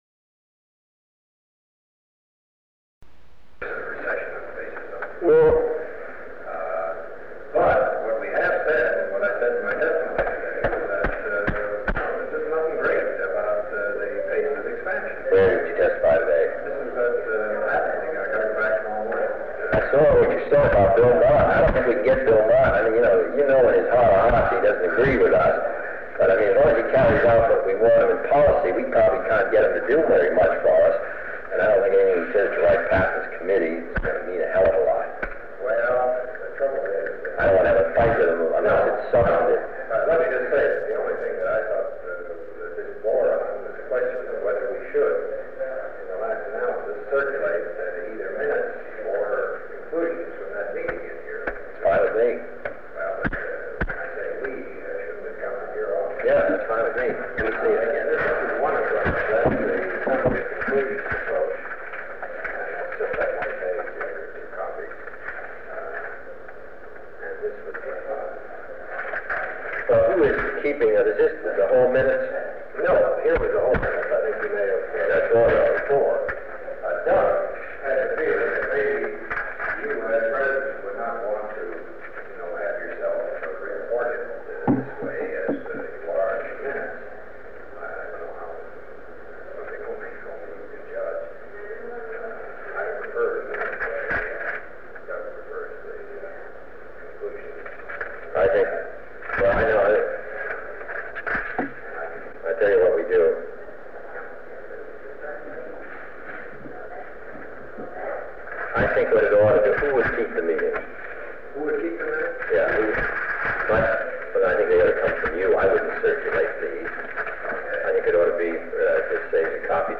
Sound recording of a meeting between President John F. Kennedy and the Chairman of the President’s Council of Economic Advisers Walter Heller, listed as a July 25, 1963, meeting, although Chairman Heller does not appear in the President’s Appointment Books that day. Heller recaps for the President his testimony on Capitol Hill earlier that day and discusses other steps to take on tax legislation.
After this tax cut meeting, there is a five-minute period during which President Kennedy speaks to various staff members about several subjects including a book of letters from children written to the President. Portions are difficult to hear.